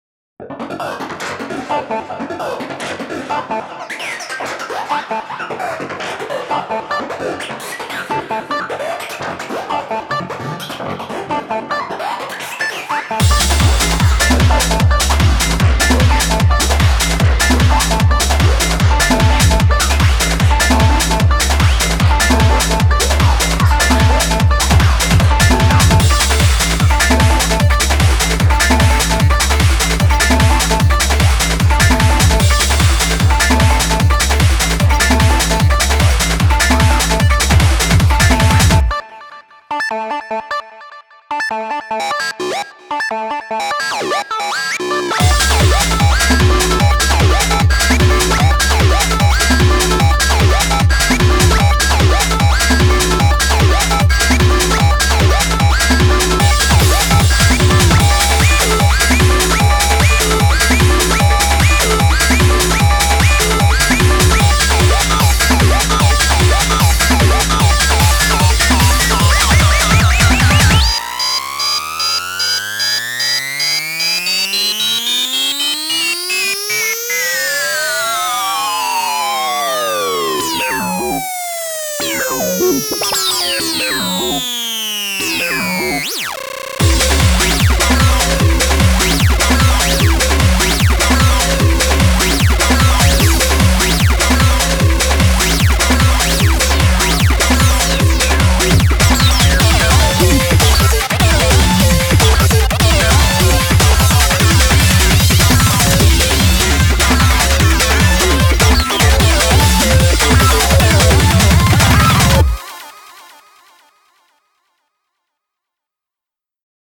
BPM150
Audio QualityPerfect (High Quality)
A cool techno song